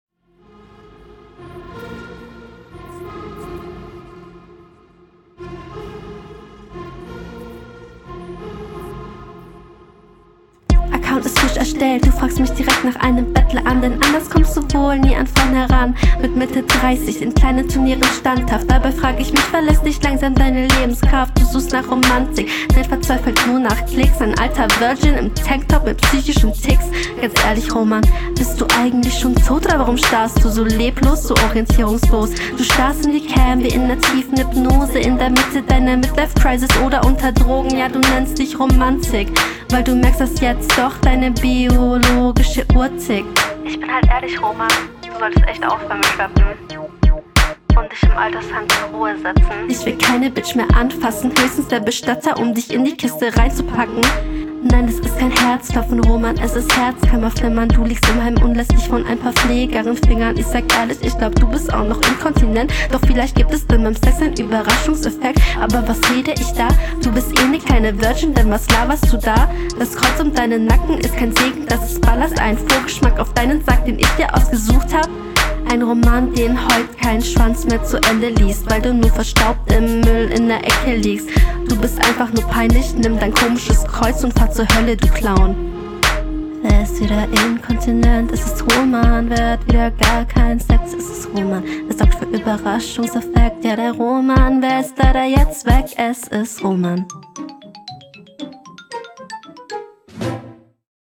Ich weiß nicht, ob ich dumm bin, aber ich hör hier nur ganz wenig Reime …